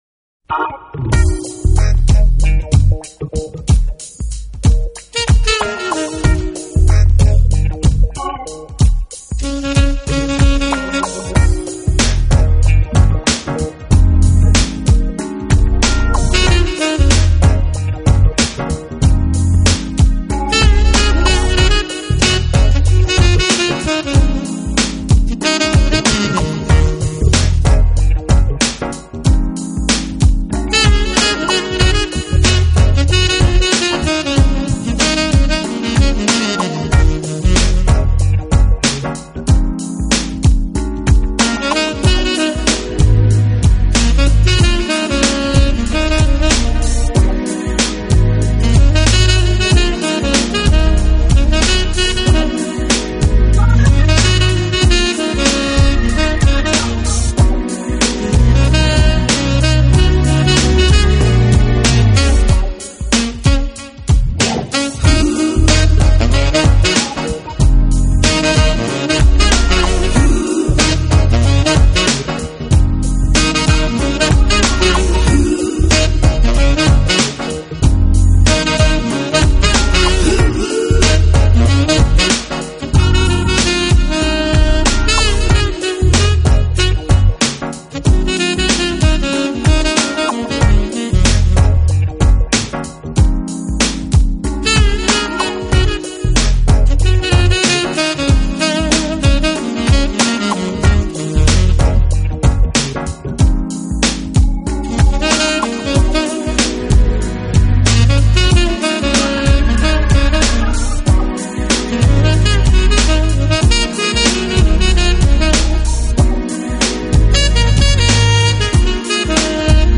Genre: Smooth Jazz